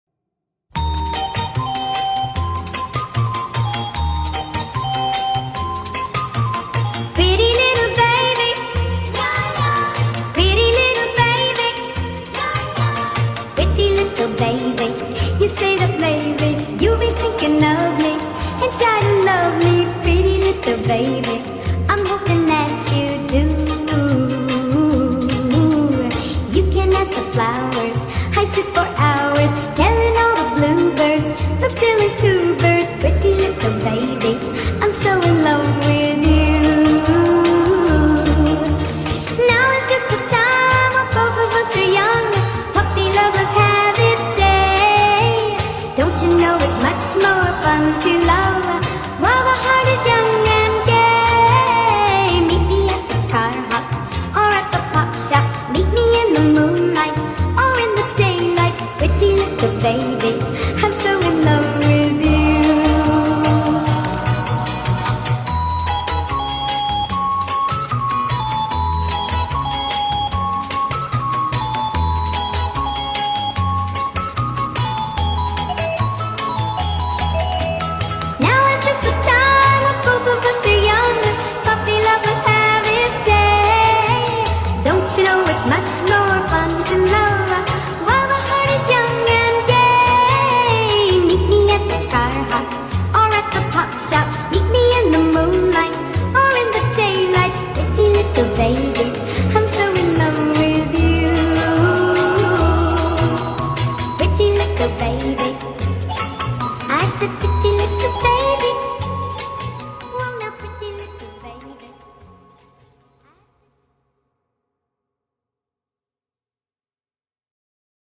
با ریتمی سریع شده